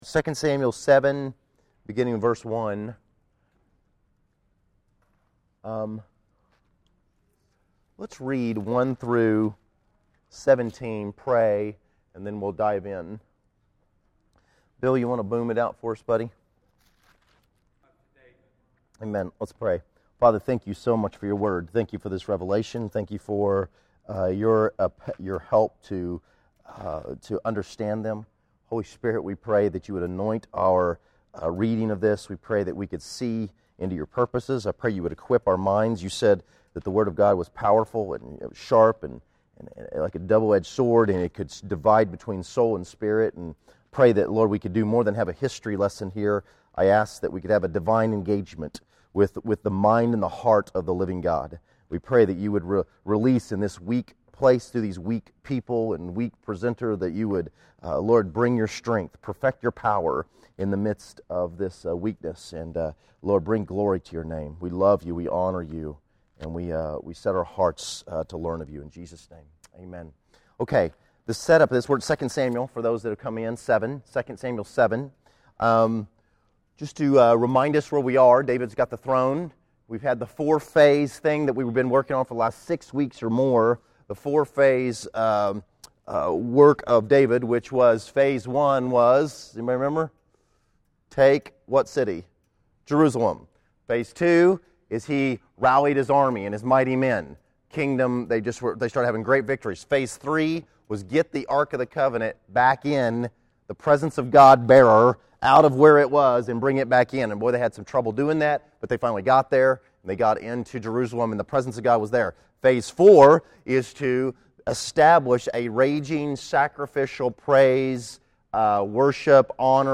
Category: Sunday School | Location: El Dorado Back to the Resource Library